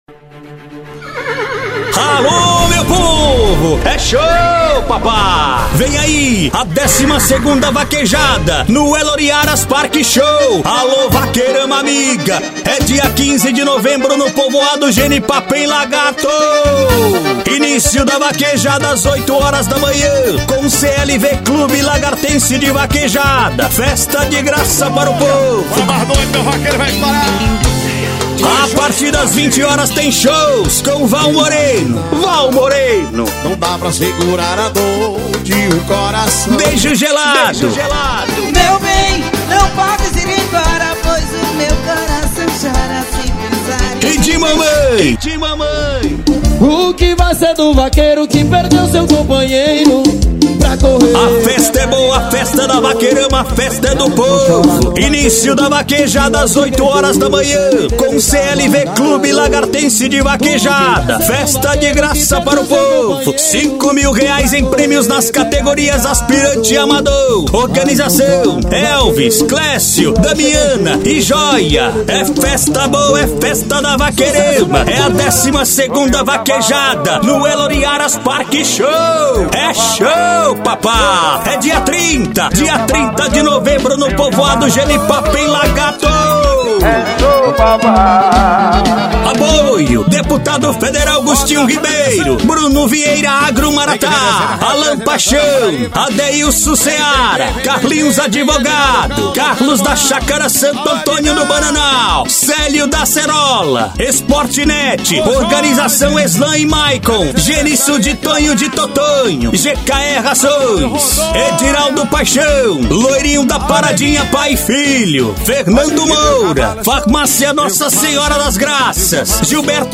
Estilo Vaquejada E Rodeio :